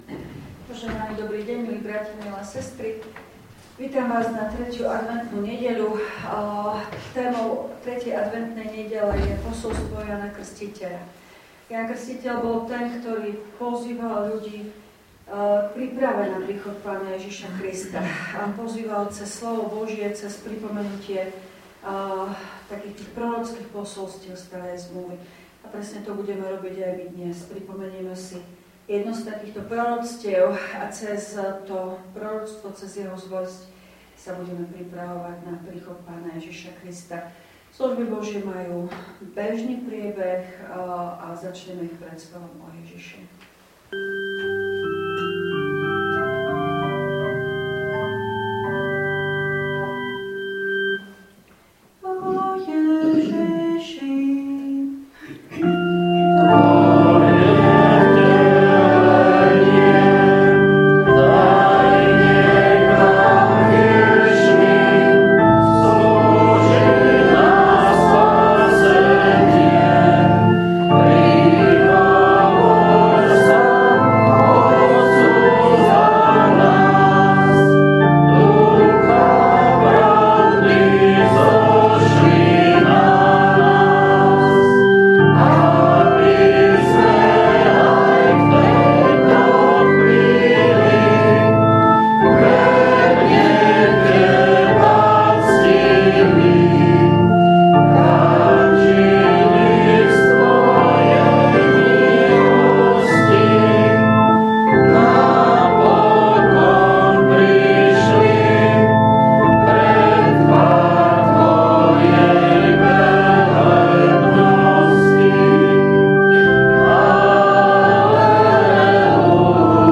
Služby Božie – 3. adventná nedeľa
V nasledovnom článku si môžete vypočuť zvukový záznam zo služieb Božích – 3. adventná nedeľa.